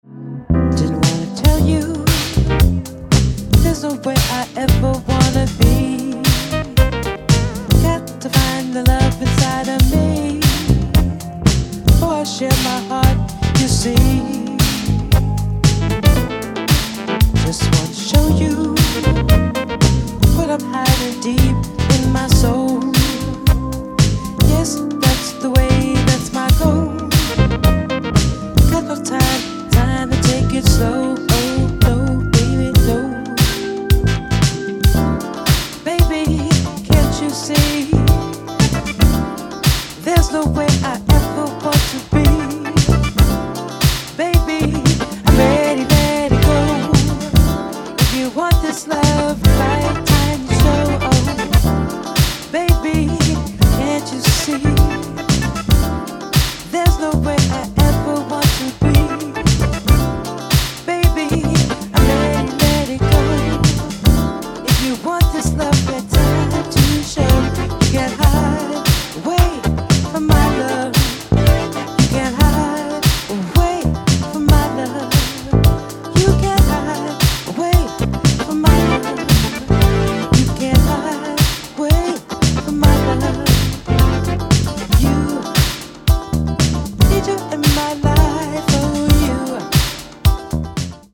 > HOUSE・TECHNO
ジャンル(スタイル) DEEP HOUSE